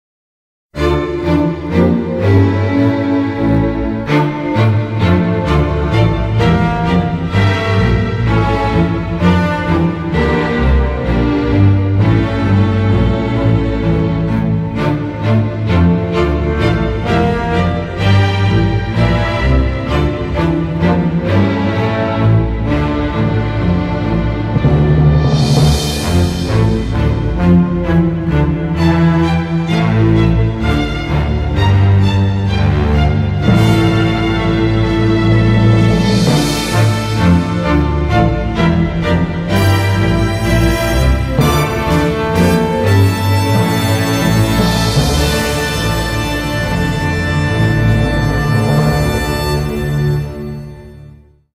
檔案 檔案歷史 檔案用途 Glory_to_Our_Prince.mp3  （MP3音訊檔，總共長52秒，位元速率192 kbps，檔案大小：1.18 MB） Anthem of Shido 檔案歷史 點選日期/時間以檢視該時間的檔案版本。